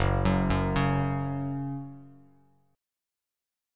F#5 Chord
Listen to F#5 strummed